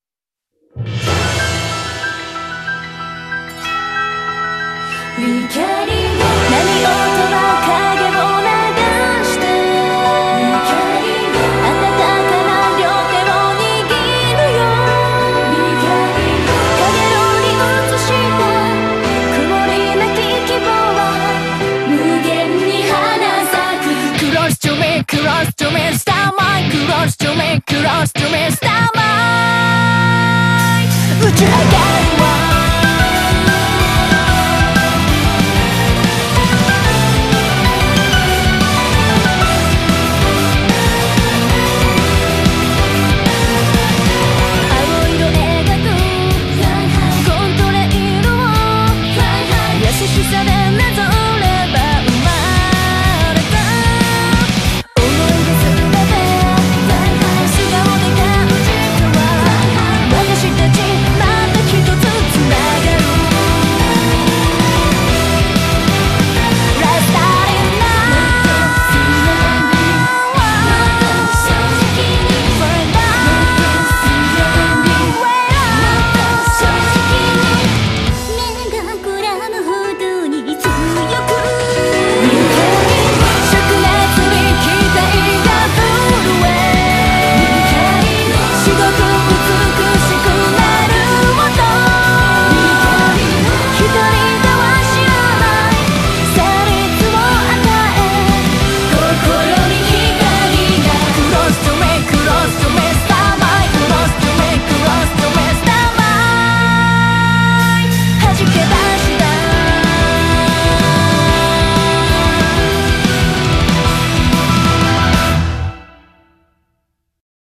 BPM93-187
Audio QualityCut From Video